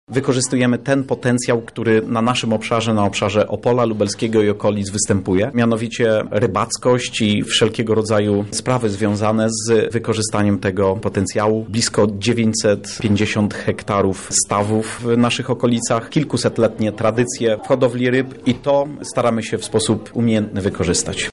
– mówi Dariusz Wróbel, prezes lokalnej grupy działania „Owocowy Szlak”, burmistrz Opola Lubelskiego.